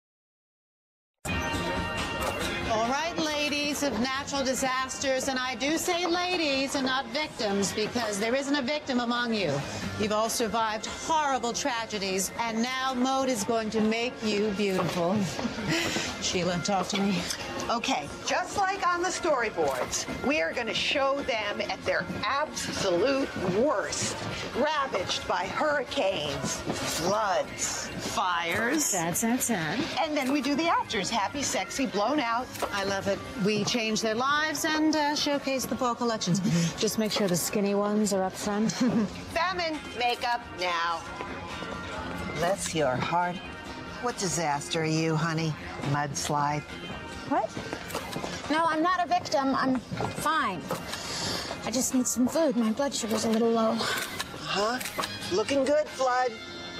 在线英语听力室影视剧中的职场美语 第39期:产品策划的听力文件下载,《影视中的职场美语》收录了工作沟通，办公室生活，商务贸易等方面的情景对话。每期除了精彩的影视剧对白，还附有主题句型。